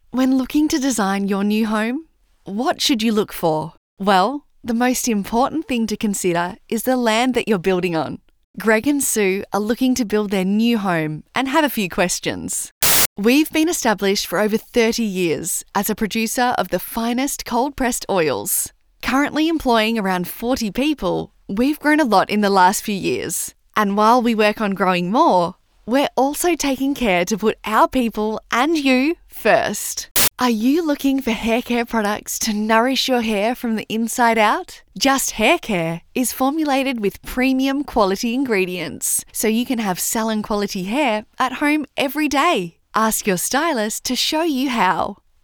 • Warm Friendly
Voice Styles
• Young
• Natural